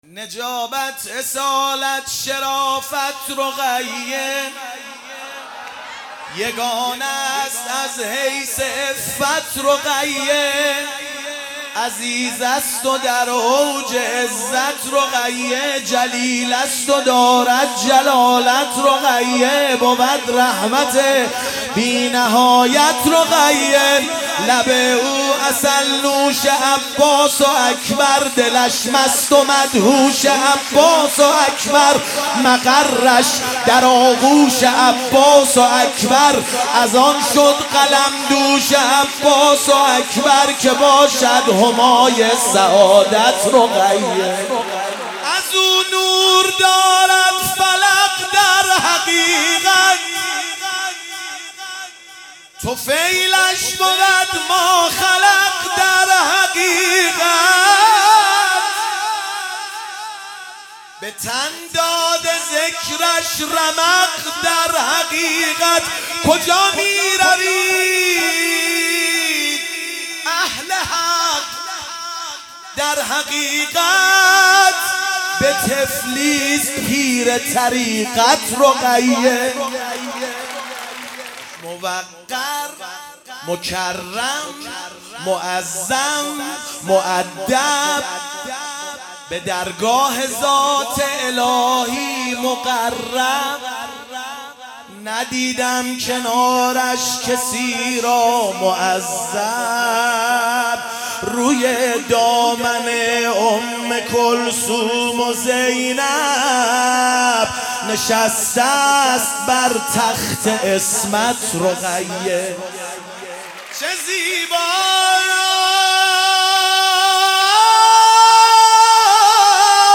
میلاد حضرت رقیه سلام الله علیها 1400 | هیئت خادمین حضرت رقیه سلام الله علیها